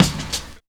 69 DIRT KICK.wav